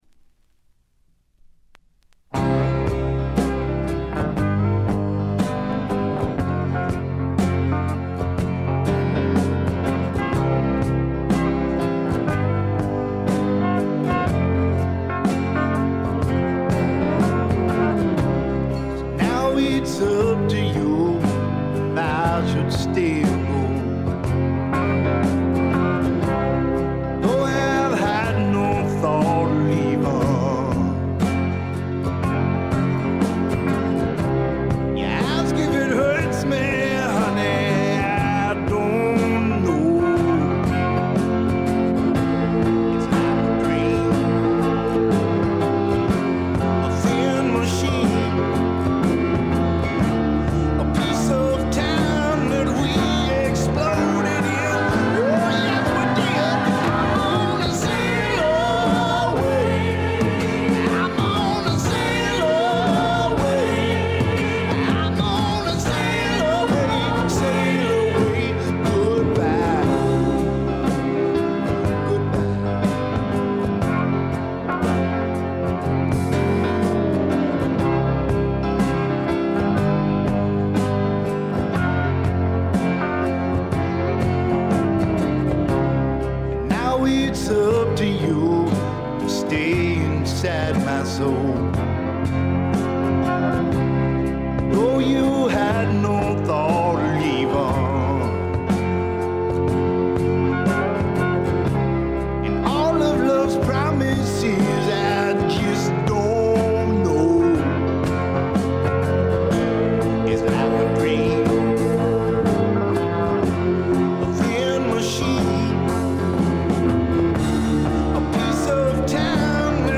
軽微なチリプチ少し。
ずばりスワンプ名作！
試聴曲は現品からの取り込み音源です。